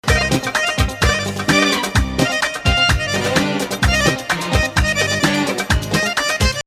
Nahawand 4